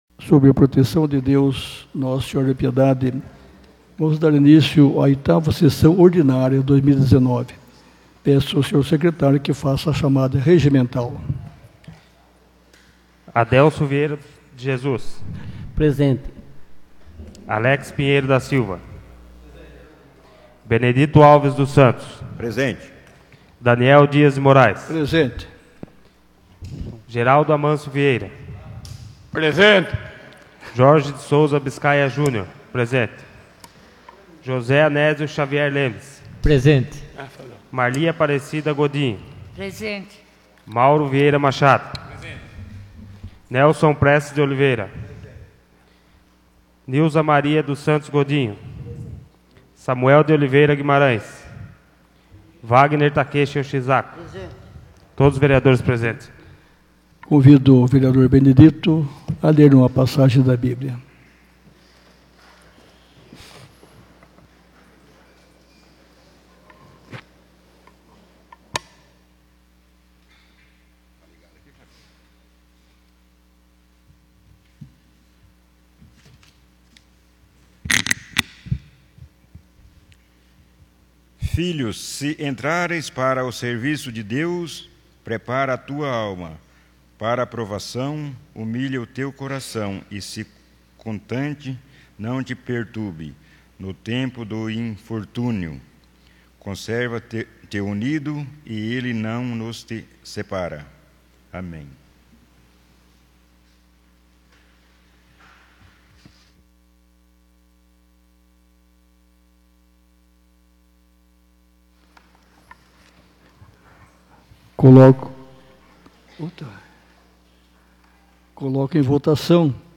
8ª Sessão Ordinária de 2019 — Câmara Municipal de Piedade